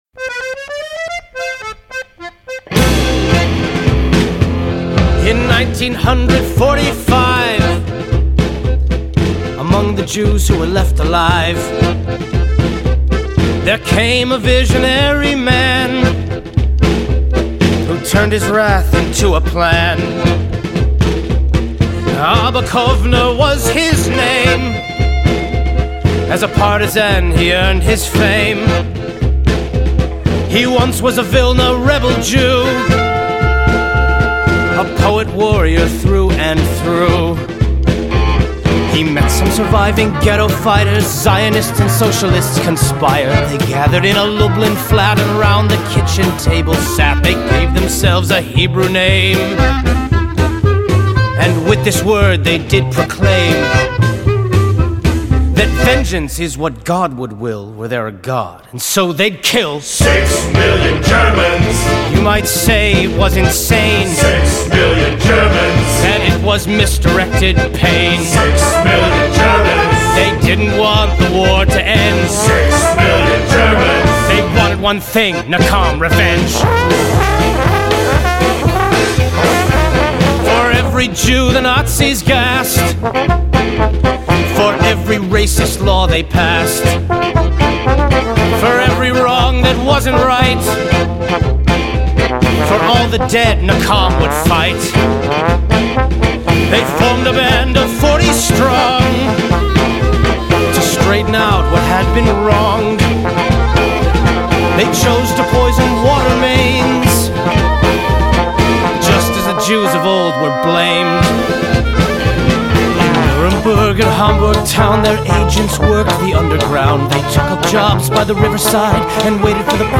הכלייזמר